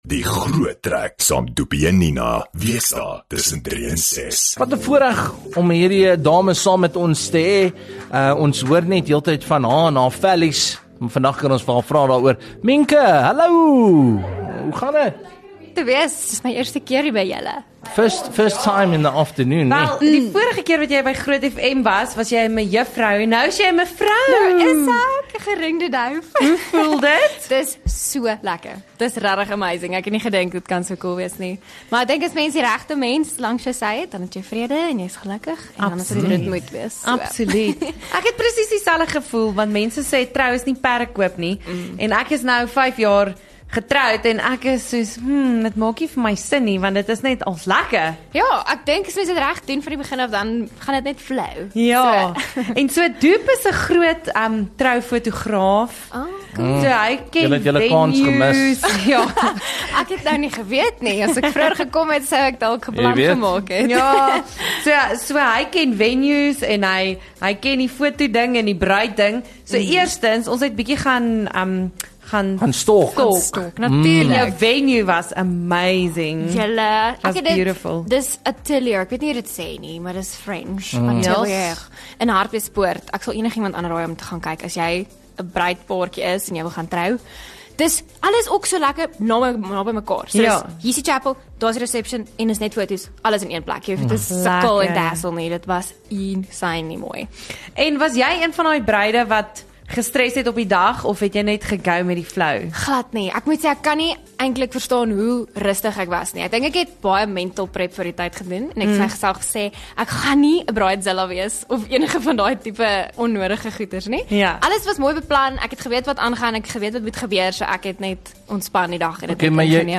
ateljee toe gebring om vir ons haar treffers akoesties op te voer